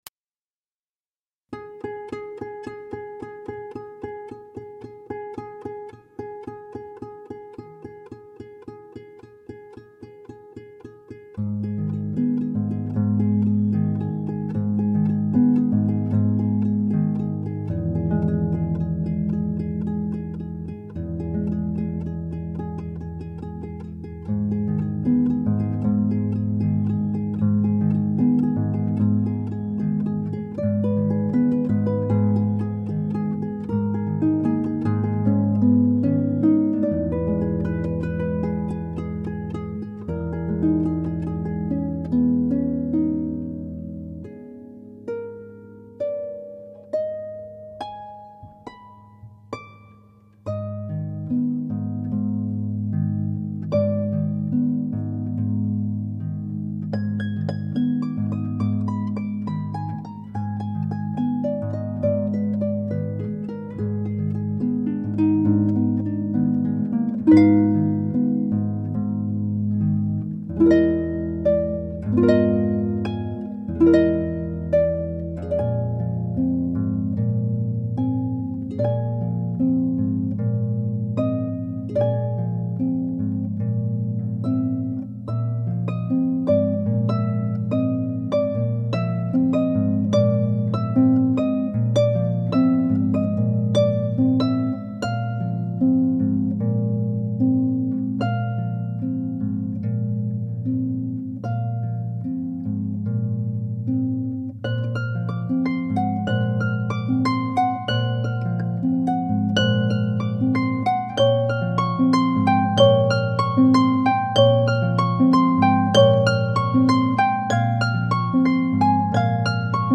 original works for pedal harp